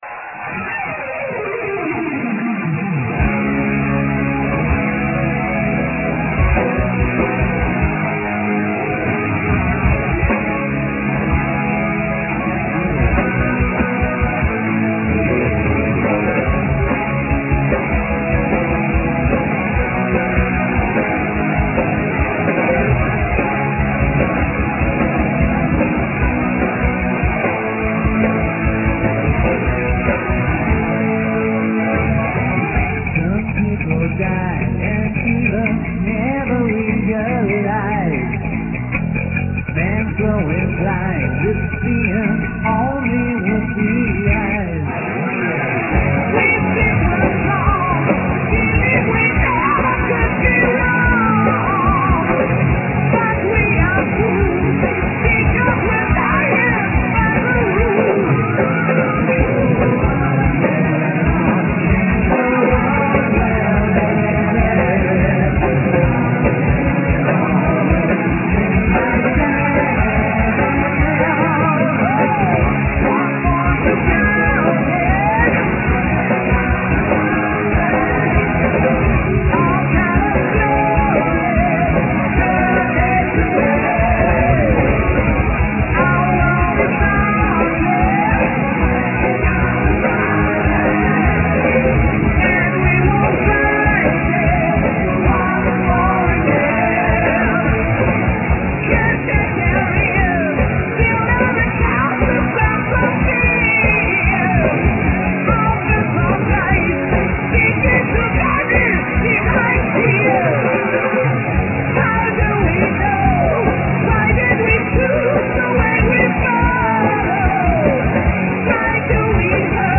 Vocals
Guitars
Bass
Drums
Keyboards